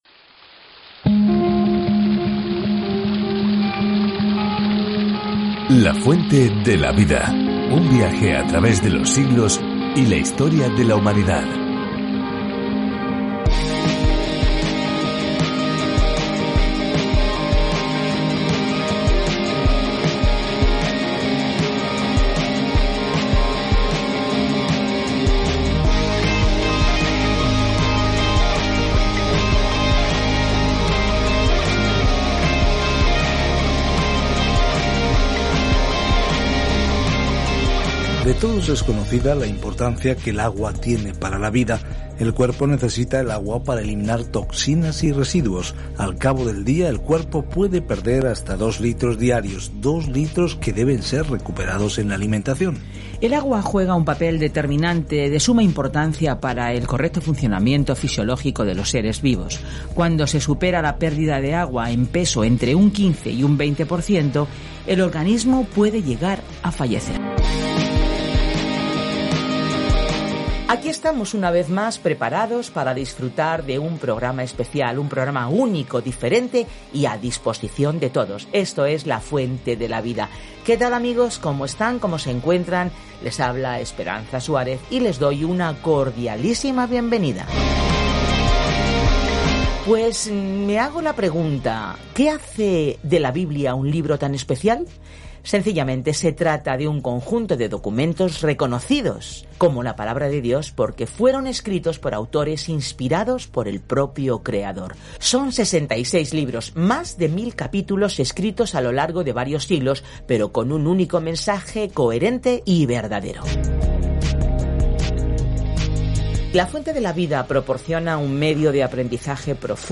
Escritura GÉNESIS 18:9-33 GÉNESIS 19:1-5 Día 19 Iniciar plan Día 21 Acerca de este Plan Aquí es donde comienza todo: el universo, el sol y la luna, las personas, las relaciones, el pecado, todo. Viaja diariamente a través de Génesis mientras escuchas el estudio de audio y lees versículos seleccionados de la palabra de Dios.